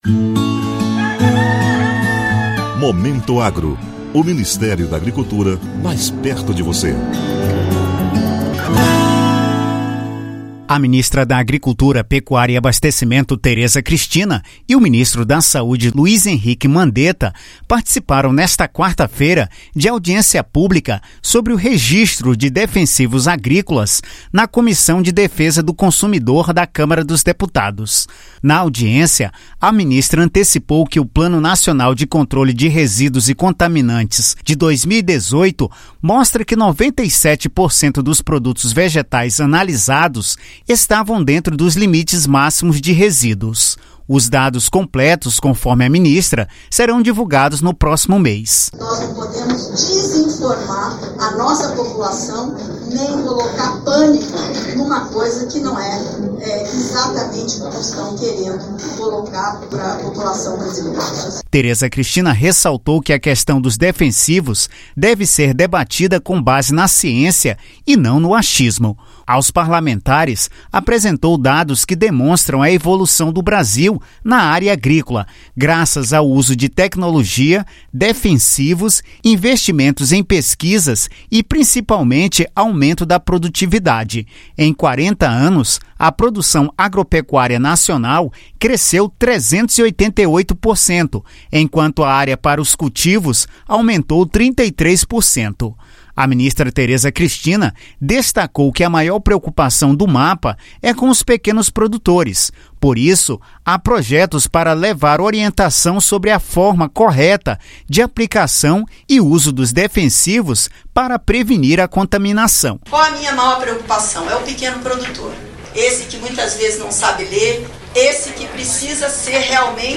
Em audiência pública na Câmara, ministra reforça segurança dos alimentos do país